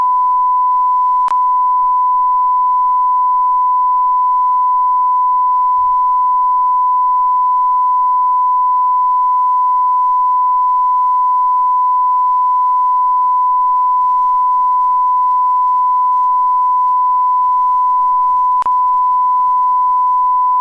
Calibrazione-94dB.wav